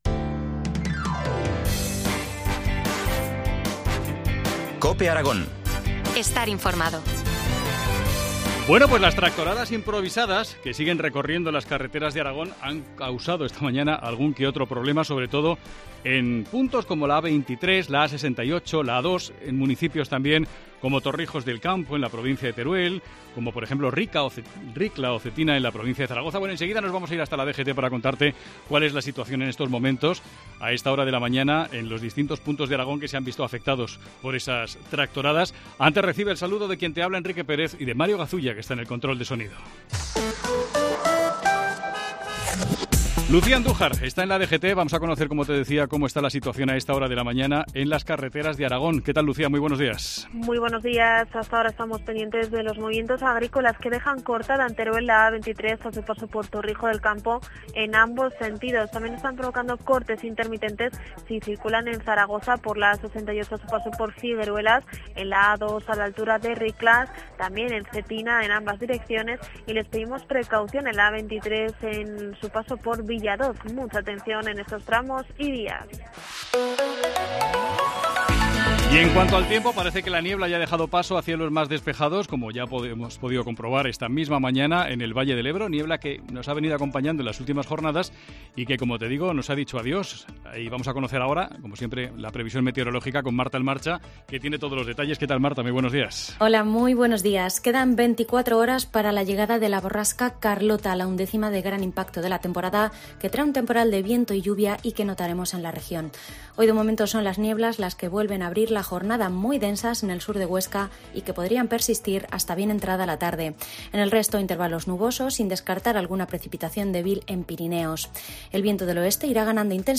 Entrevista del día en COPE Aragón